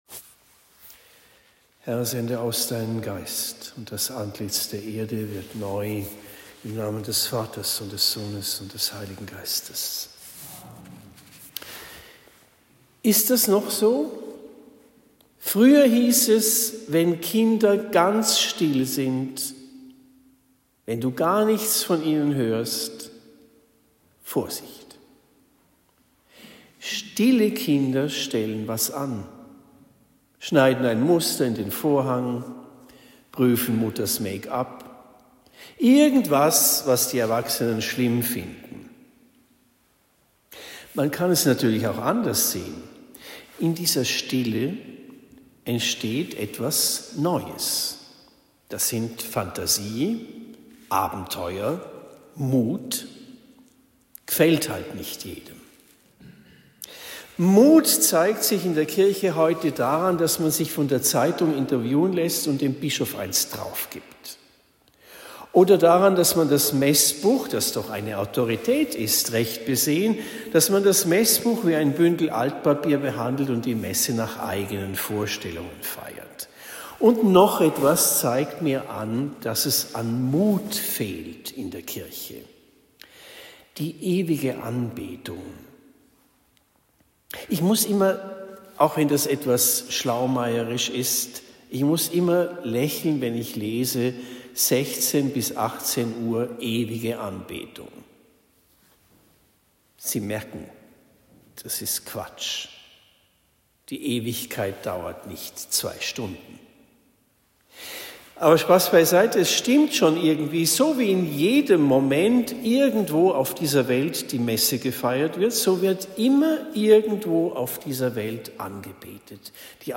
Predigt am 21. Jänner 2025 in Hafenlohr